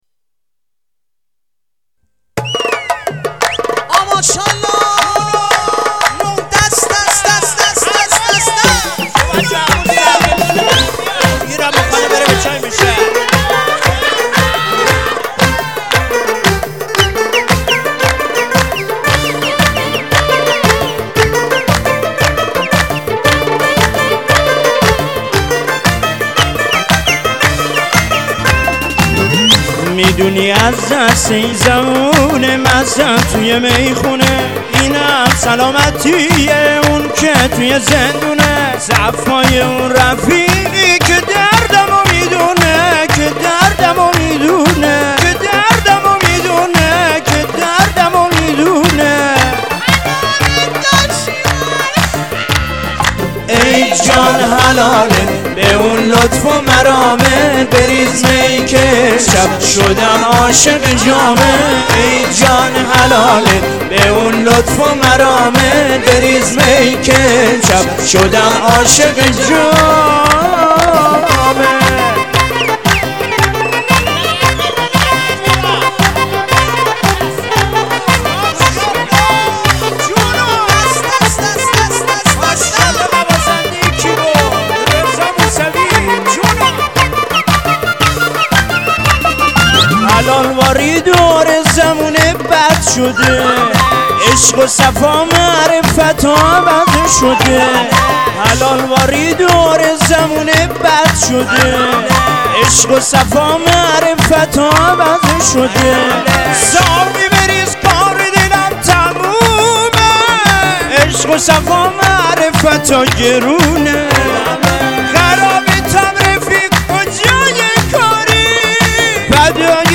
ترانه مازندرانی